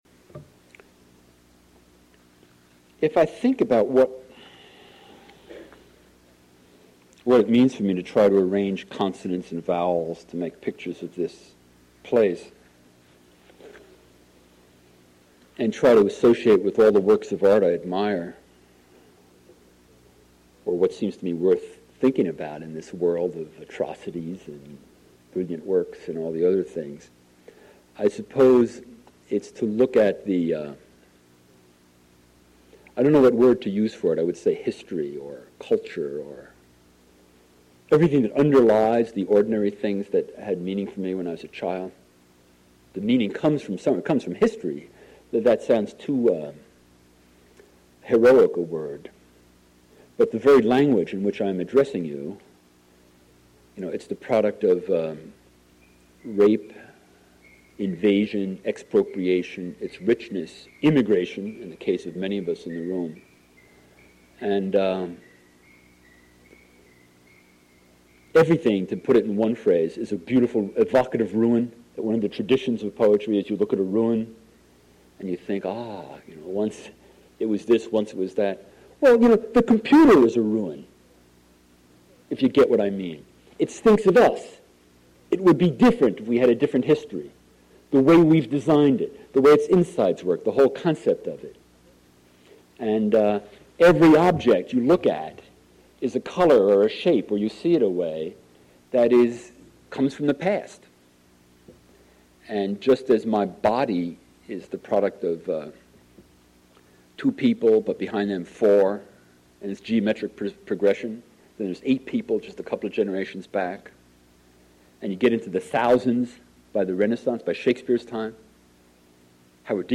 Reader
Reading